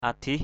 /a-d̪ih/ (d.) phương = point cardinal. cardinal point. adih pur ad{H p~R phương đông = est = east. adih pai ad{H =p phương tây = ouest = west. adih ut...
adih.mp3